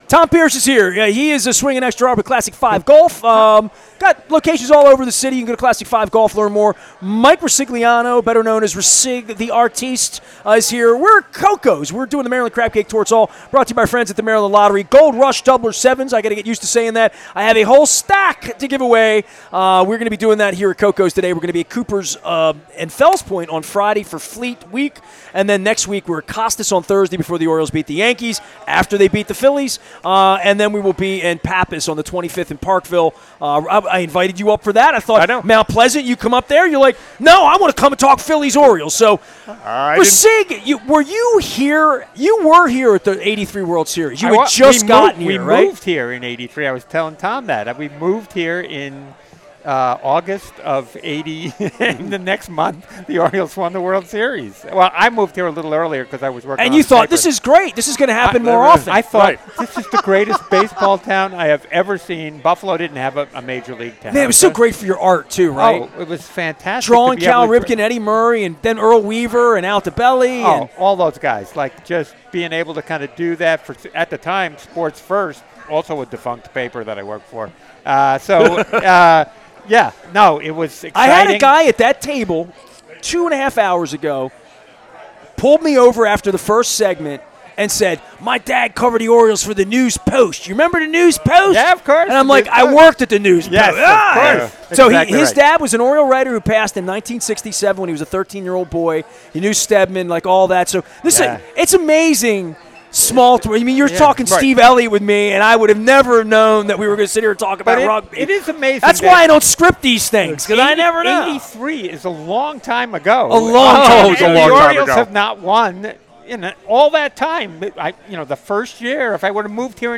at Koco's Pub in Lauraville on the Maryland Crab Cake Tour